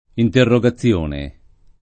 interrogazione [ interro g a ZZL1 ne ] s. f.